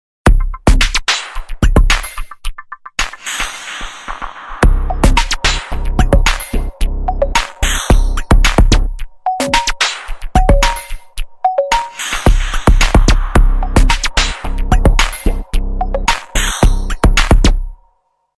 таинственные
Прикольный рингтон смс